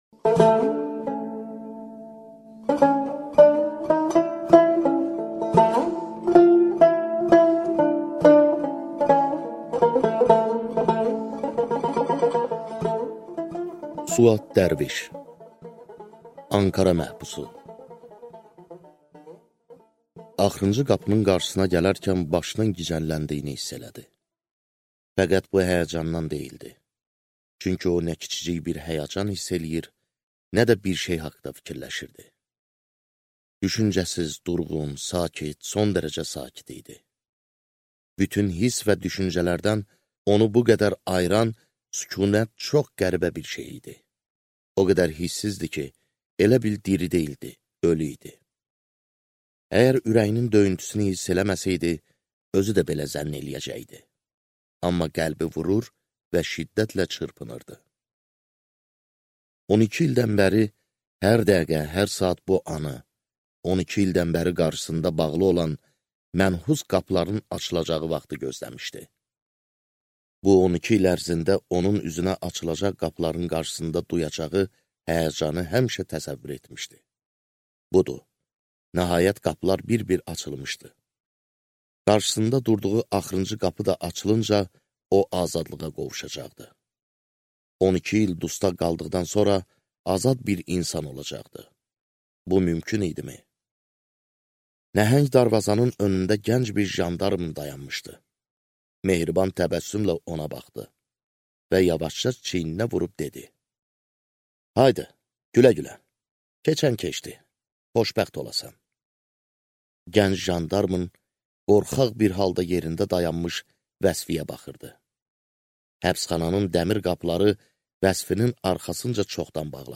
Аудиокнига Ankara məhbusu | Библиотека аудиокниг